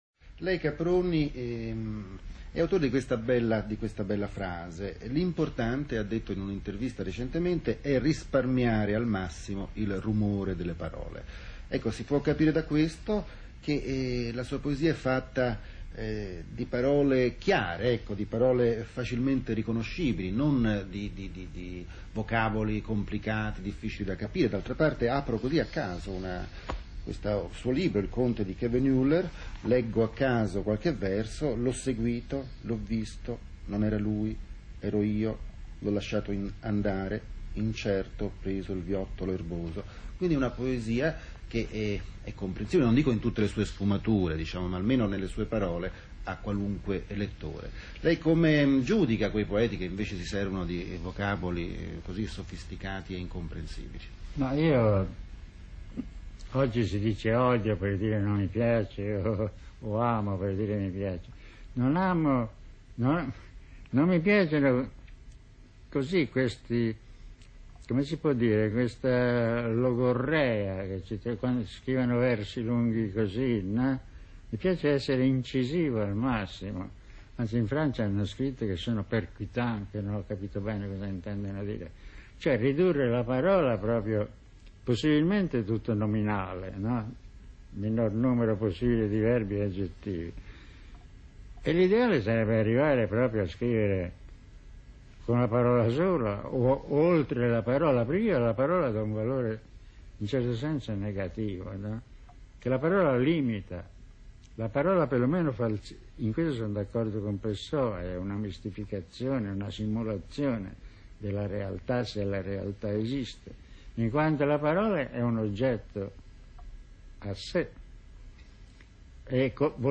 Intervista a Giorgio Caproni sul linguaggio della poesia [2:23m]: Play Now | Play in Popup | Download